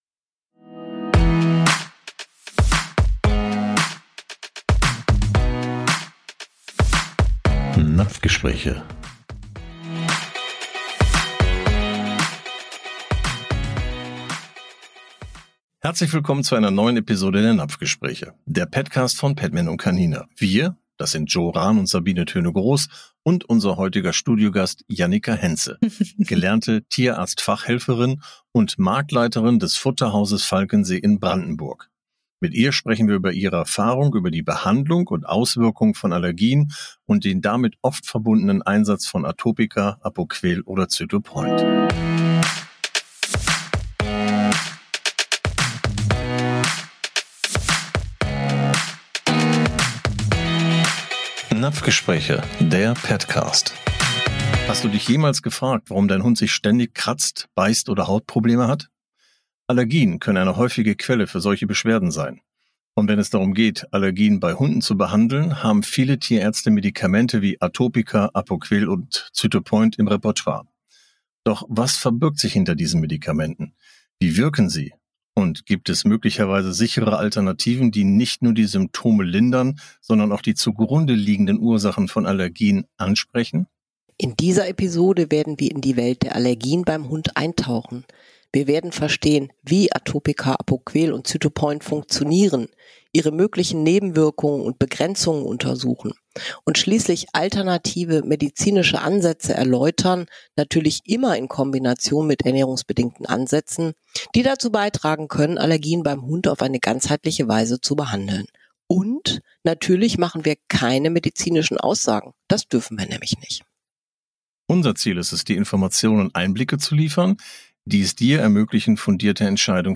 mit Studiogast